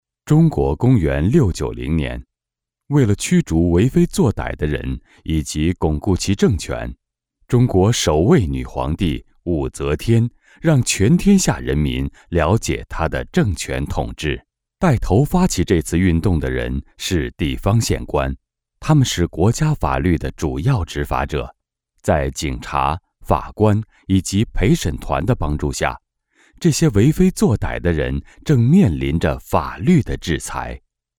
Masculino
e-learning/training
I have my own home studio which can provide qualified recording.